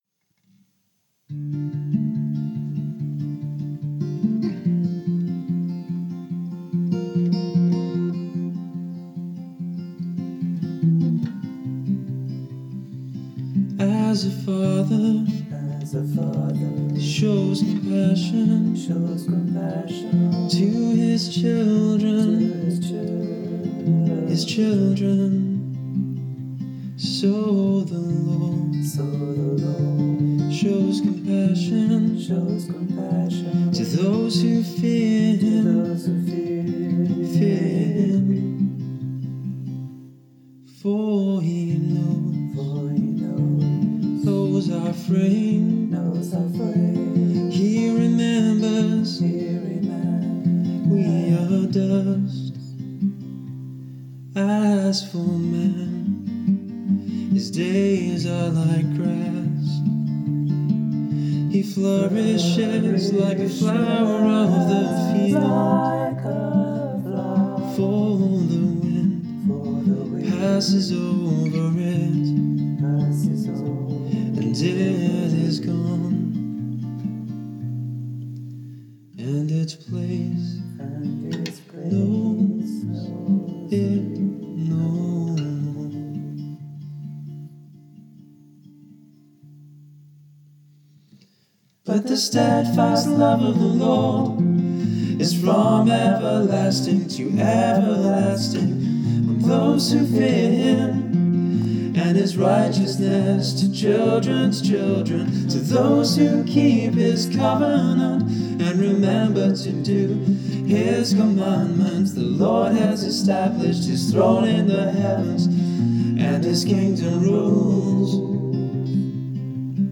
The backing vocals (writing and recording) are his.